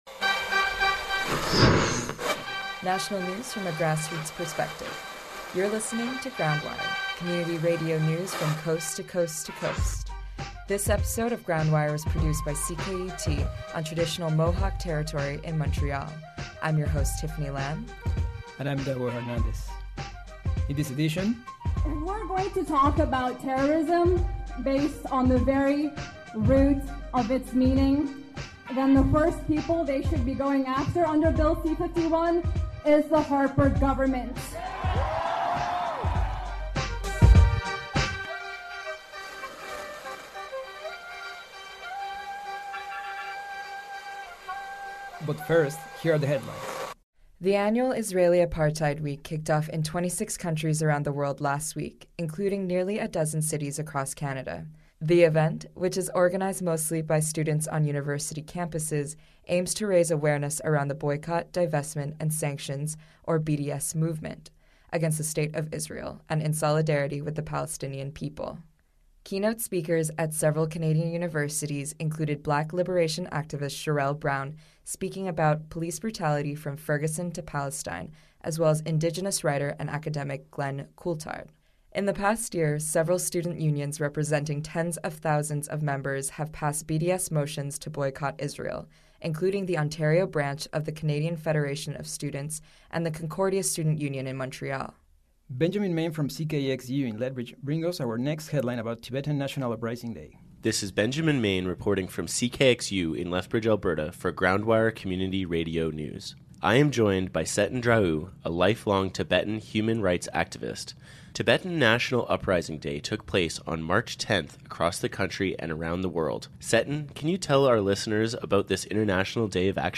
GroundWire: Community Radio News